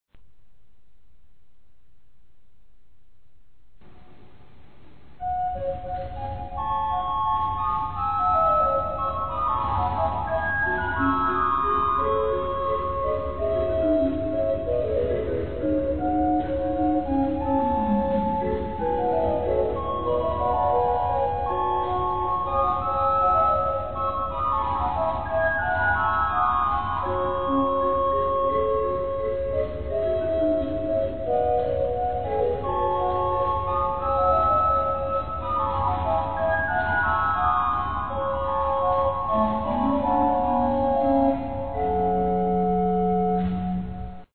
FILES AUDIO DAL VIVO
(organo della chiesa di Saint Martin a Dieppe - Normandia - Francia)
organo